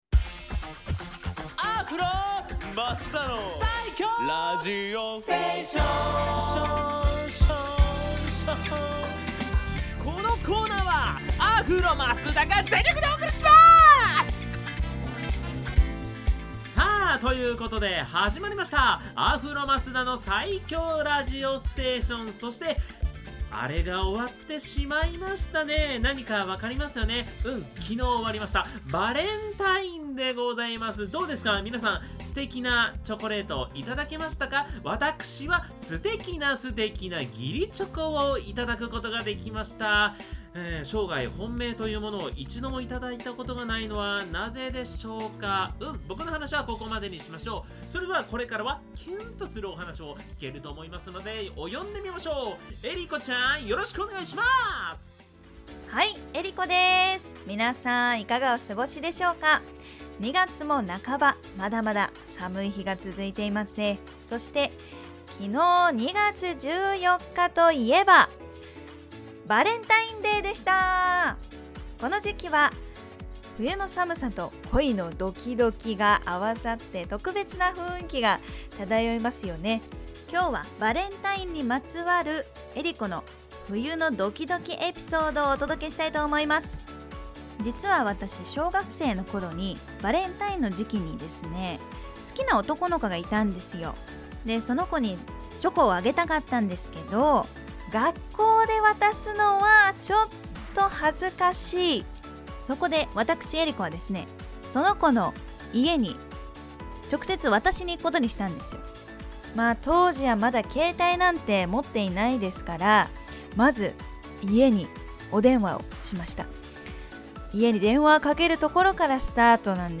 こちらのブログでは、FM83.1Mhzレディオ湘南にて放送されたラジオ番組「湘南MUSICTOWN Z」内の湘南ミュージックシーンを活性化させる新コーナー！